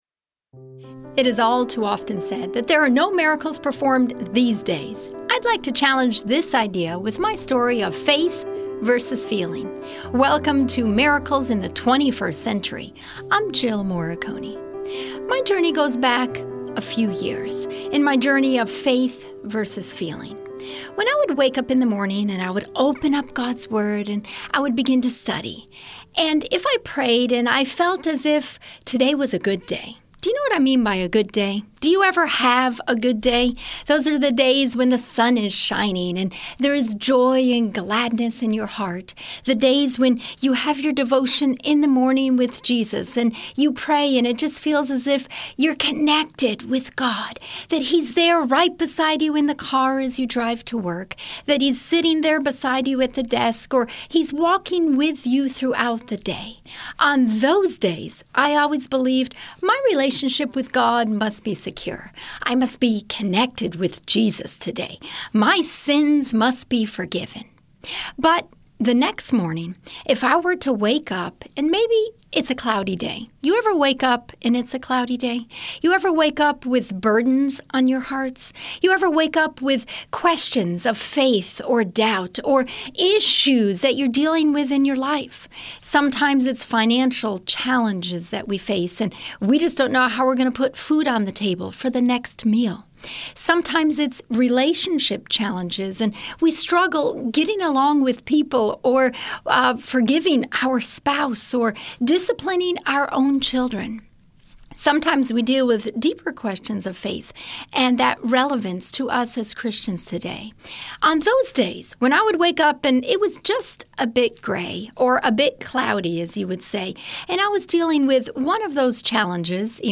Yes, listen to people share real life miracle stories, not only from recent times but also amazing stories from their past.